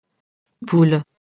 poule [pul]